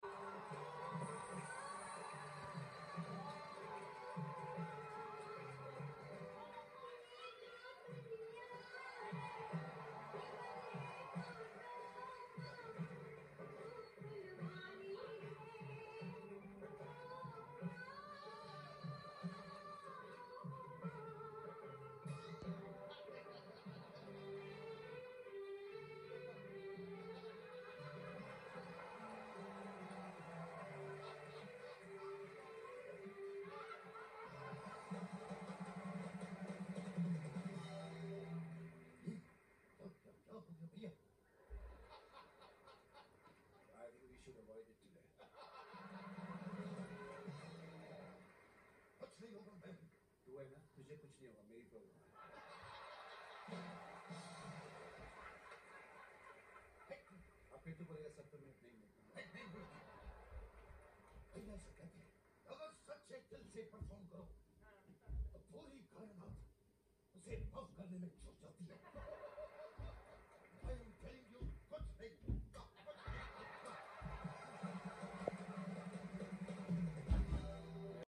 SRK & Salman imitated . sound effects free download